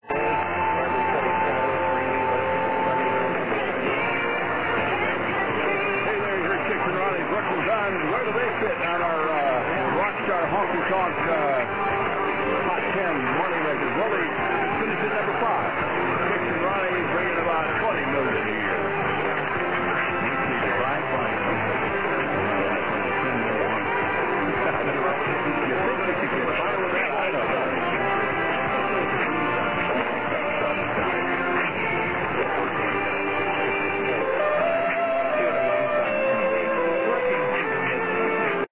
Here's some clips of the station recorded before midnight: